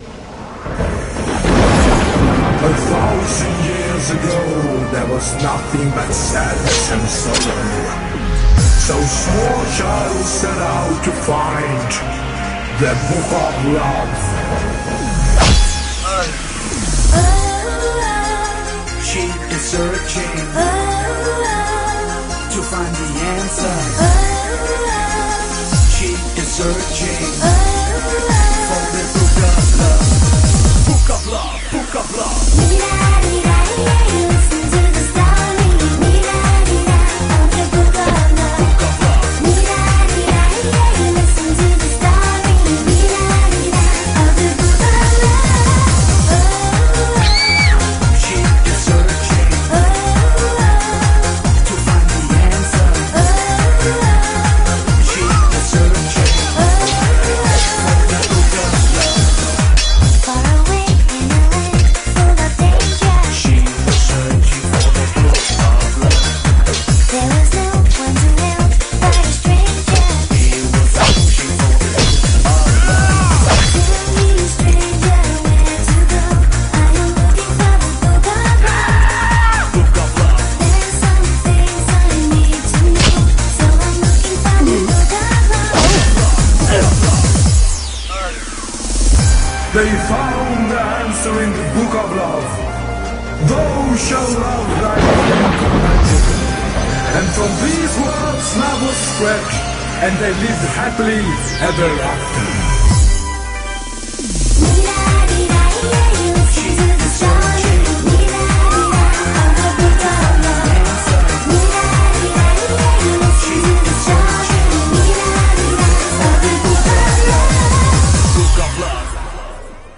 BPM139-140
Audio QualityCut From Video
With sound effects.